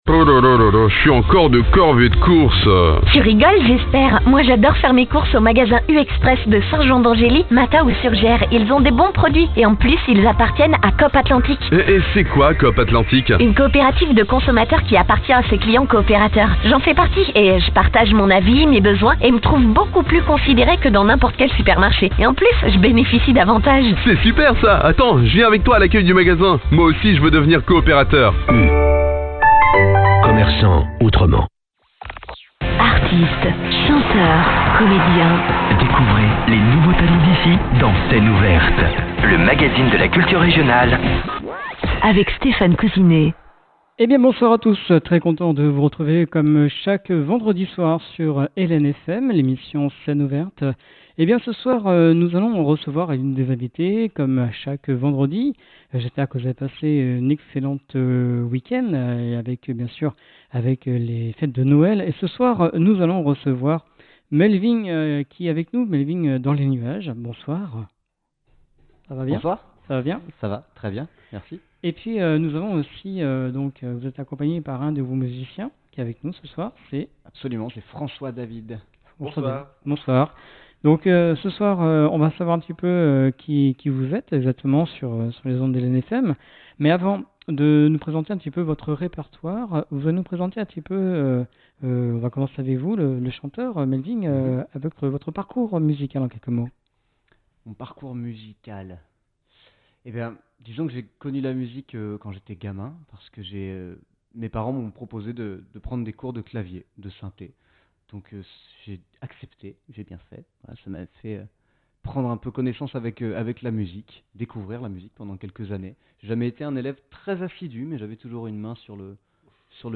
De la chanson française actuelle, fraîche et poétique, sur une musique riche et variée qui nous emmène de la cour de récréation jusqu’en Afrique, du voyage à pied jusqu’au voyage intérieur.